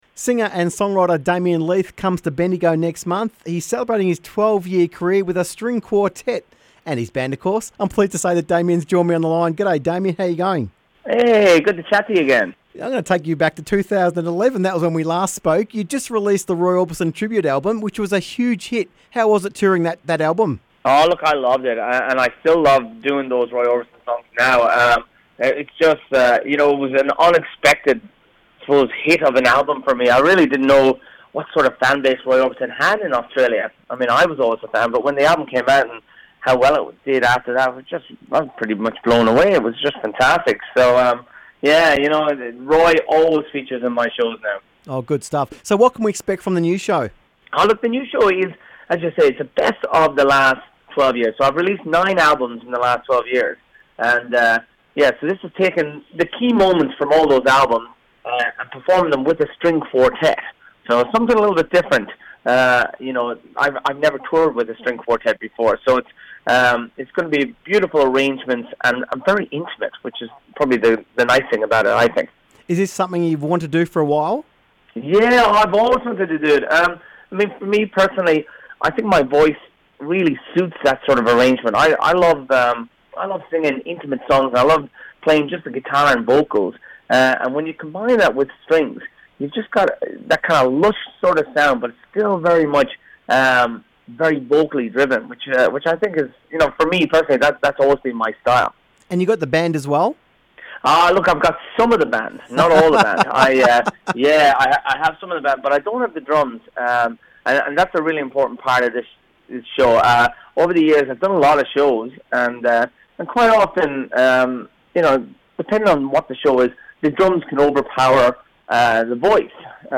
Damien Leith Interview 30/03/19